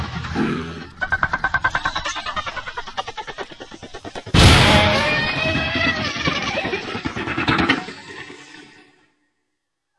Рычание и страшные звуки: